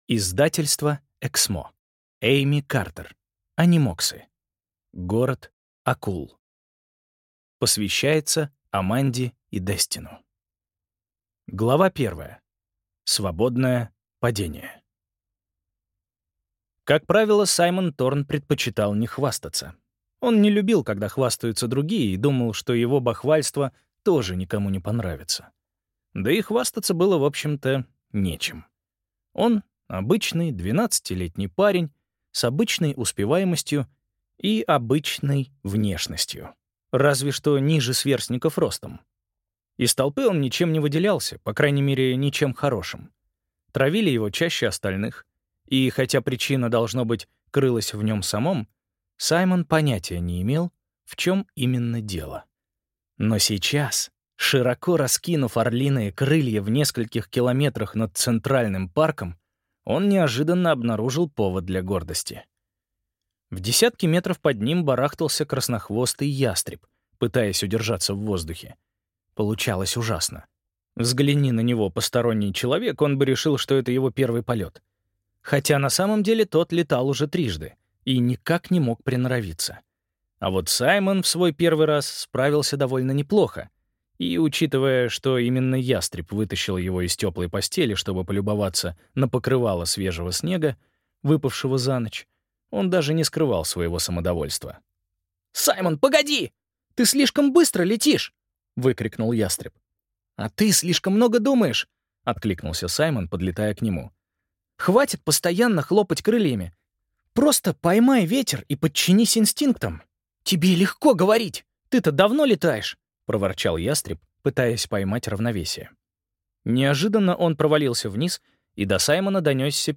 Аудиокнига Город акул | Библиотека аудиокниг